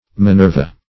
Minerva \Mi*ner"va\, n. [L.] (Rom. Myth.)